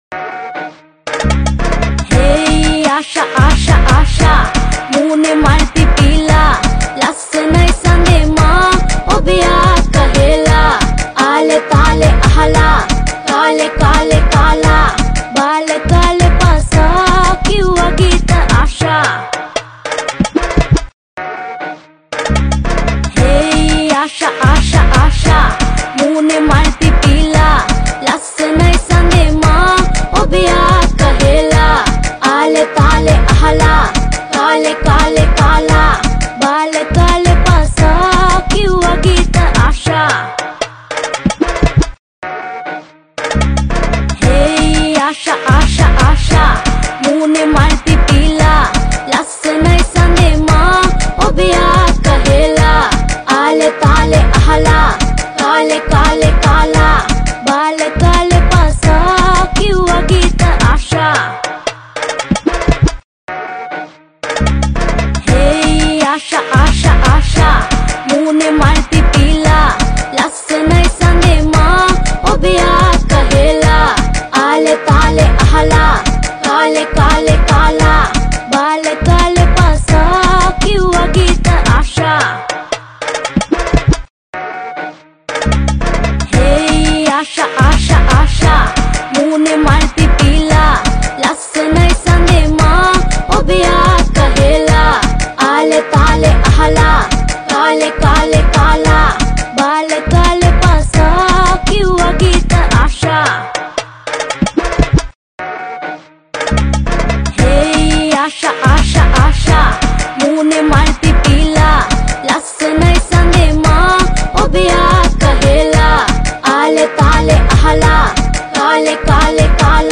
sinhala dance songs
sinhala dj song